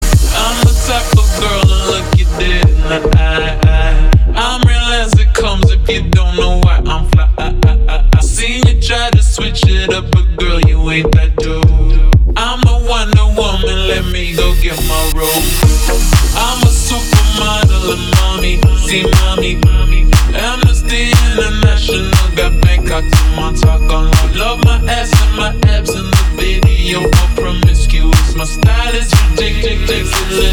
• Качество: 320, Stereo
Club House
клубняк
Крутая клубная музыка, в машине слушать кайф!